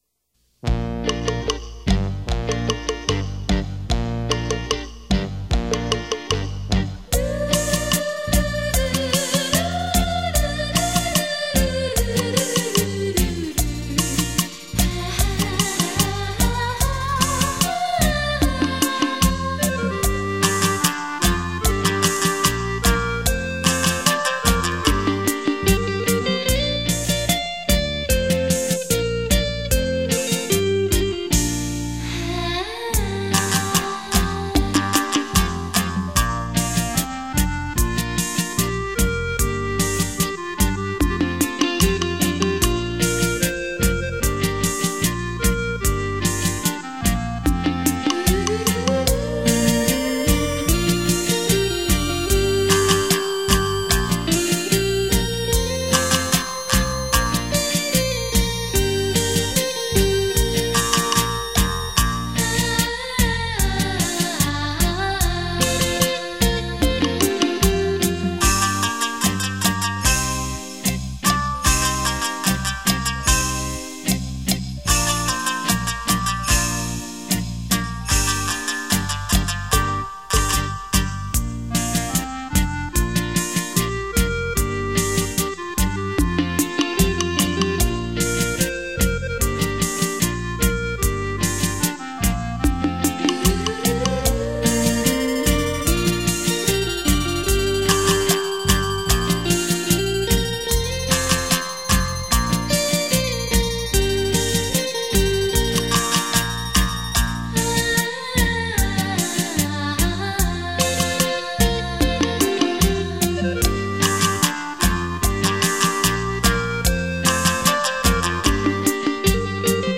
优雅的乐音
怀念的曲调
三味线的悠扬乐声中